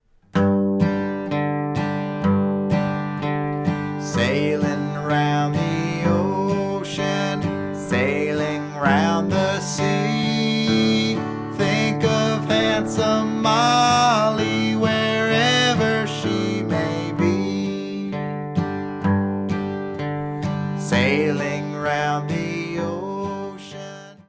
Slow version, chorus only (key of G)